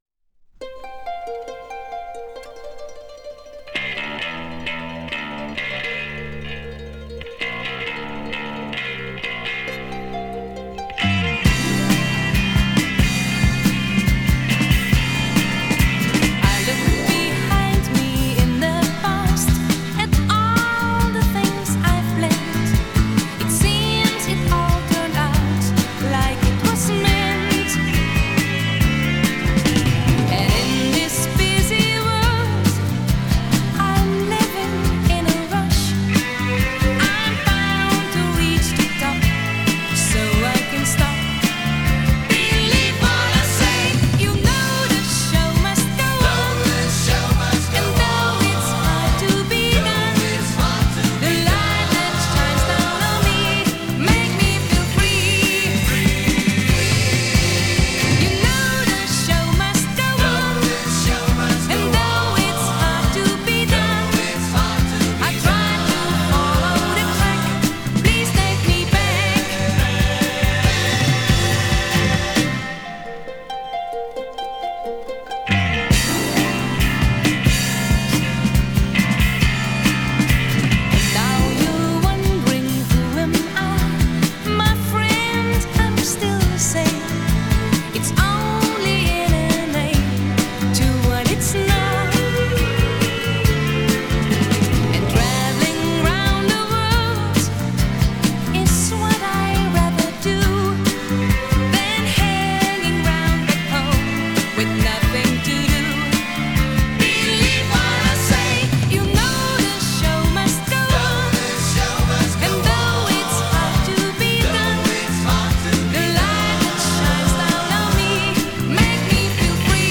Жанр: Electronic, Rock, Funk / Soul, Pop
Recorded At – Dureco Studio